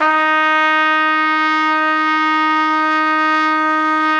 Index of /90_sSampleCDs/Best Service ProSamples vol.20 - Orchestral Brass [AKAI] 1CD/Partition A/VOLUME 002